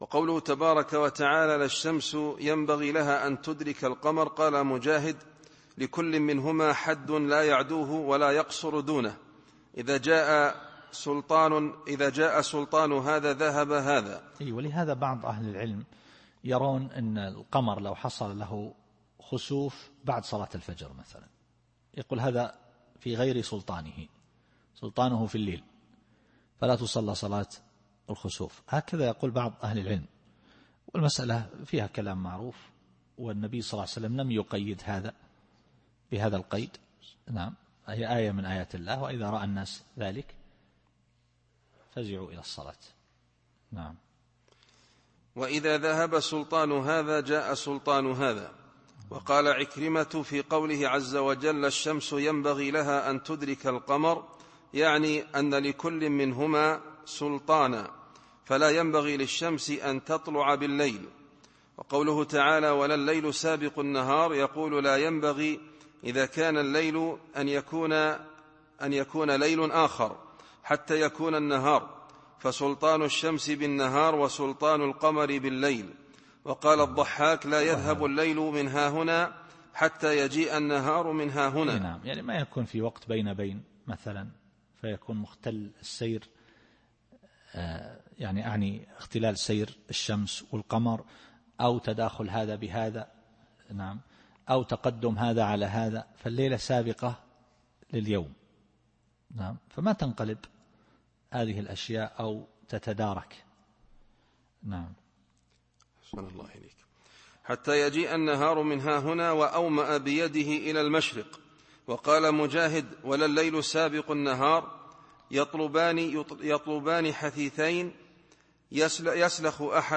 التفسير الصوتي [يس / 40]